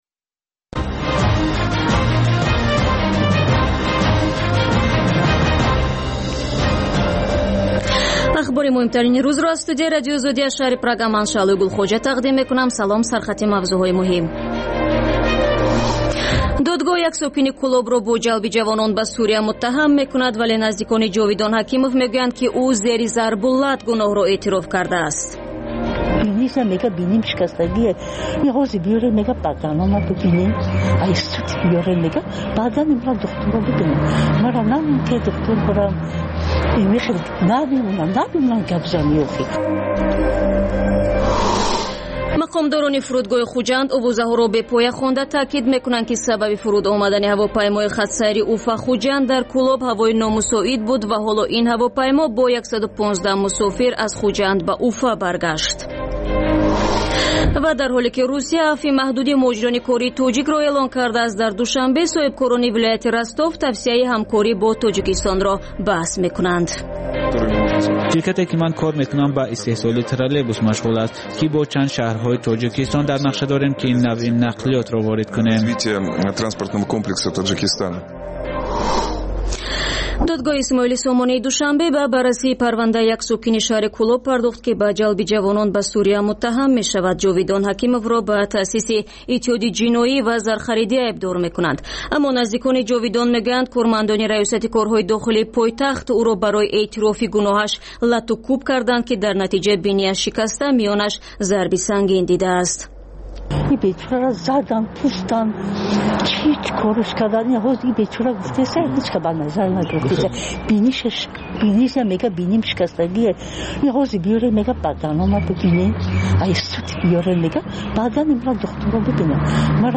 Тозатарин ахбор ва гузоришҳои марбут ба Тоҷикистон, минтақа ва ҷаҳон дар маҷаллаи шомгоҳии Радиои Озодӣ